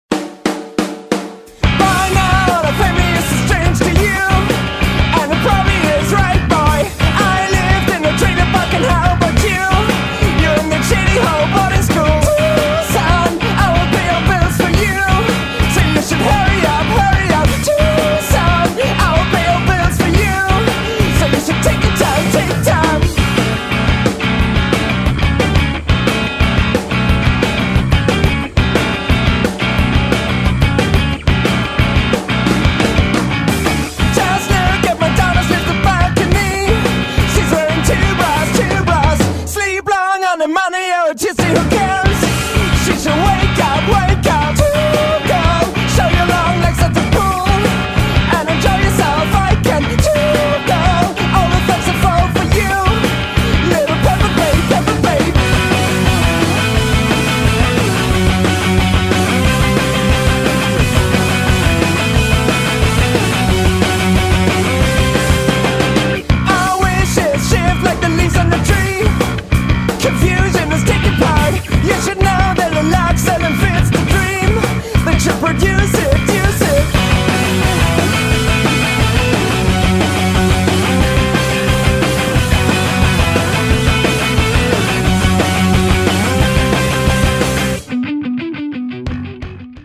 Indierock